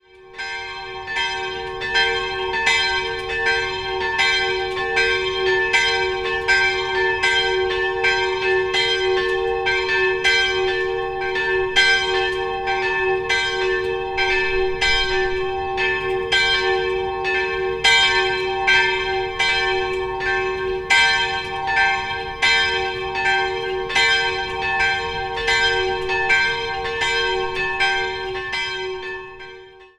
Der schöne Hochaltar mit einer Figur des Kirchenpatrons stammt aus dem Jahr 1680. 2-stimmiges Geläut: g''-a'' Die größere Glocke wurde 1952 von Rudolf Perner in Passau gegossen. Die kleinere stammt wohl aus dem 15. Jahrhundert und entstand in Nürnberg.